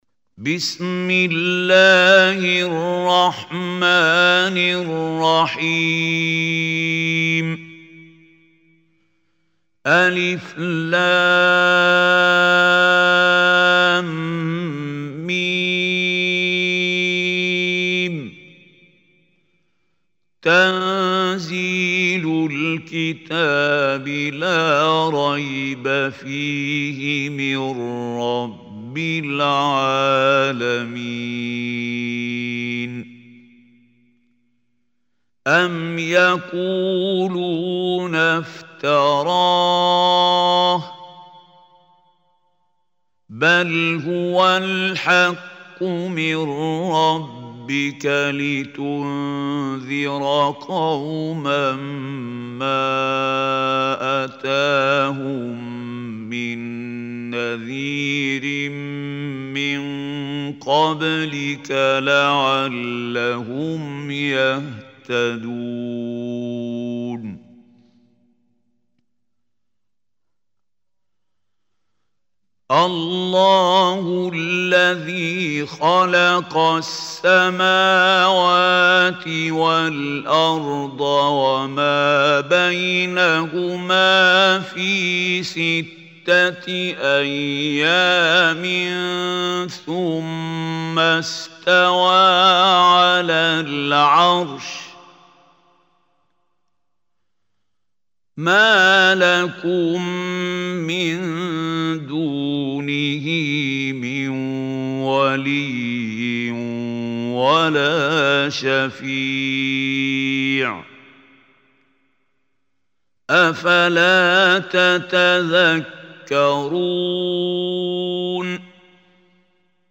Surah As Sajdah Recitation by Khalil Al Hussary
Surah As-Sajdah is 32nd chapter of Holy Quran. Listen beautiful recitation of Surah As Sajdah in the voice of Mahmoud Khalil Al Hussary.